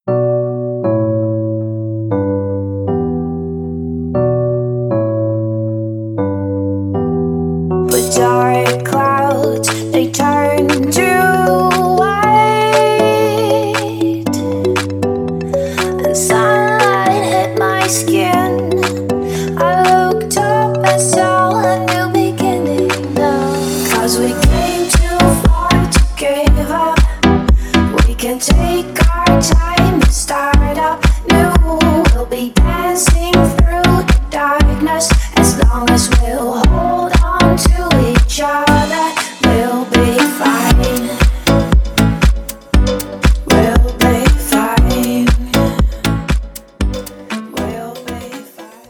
красивые
dance
спокойные